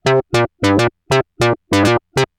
/audio/sounds/Extra Packs/musicradar-synth-samples/ARP Odyssey/Arp B Lines/
Arp B Line 01.wav